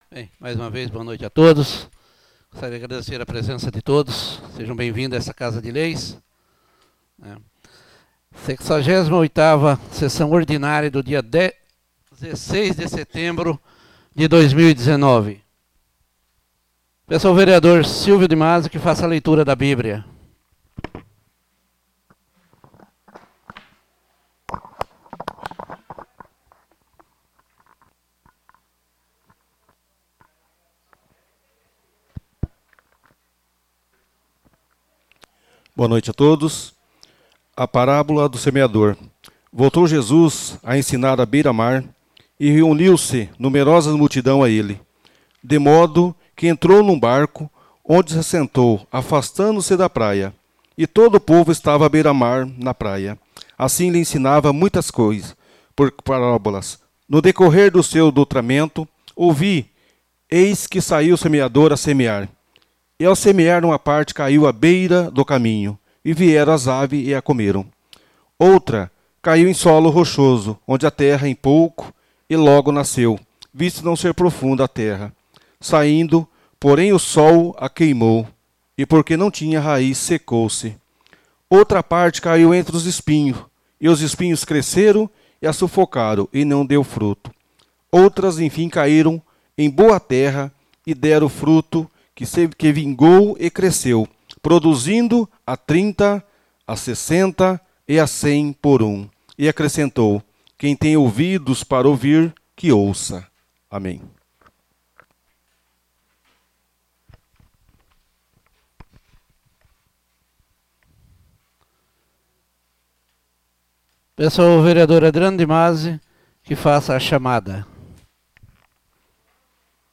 Áudio Sessão Ordinária 68/2019